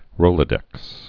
(rōlə-dĕks)